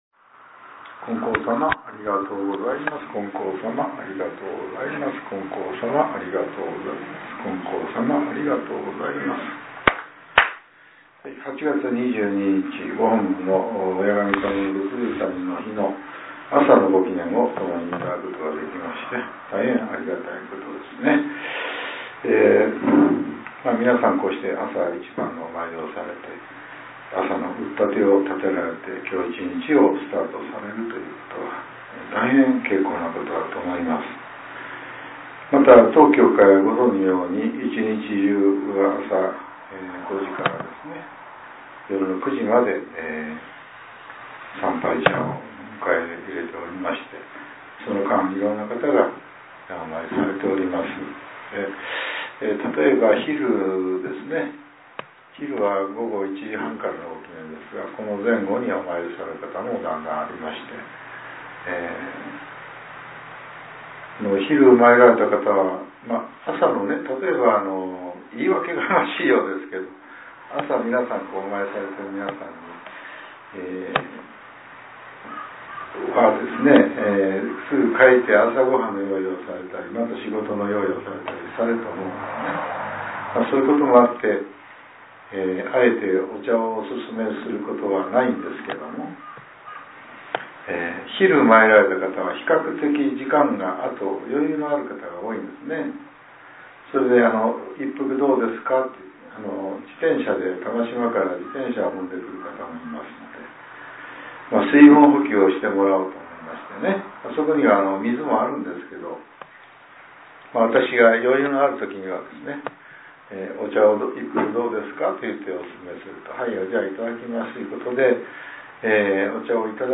こちらは、元日祭の祭詞と、ことしの当教会の祈願詞です。